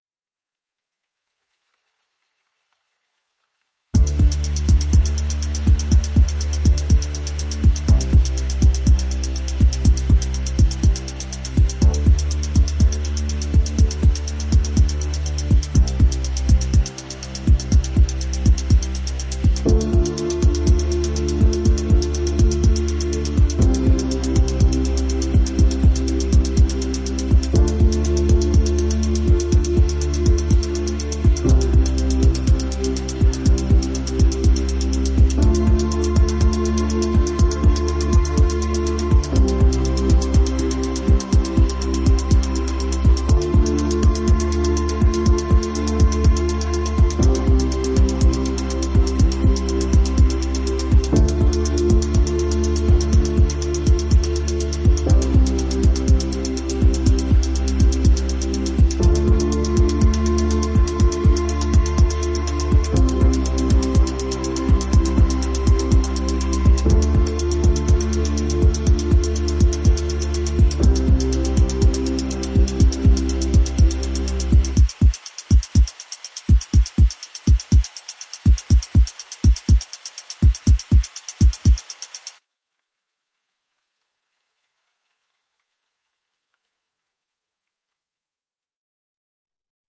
ambient_0411_2.mp3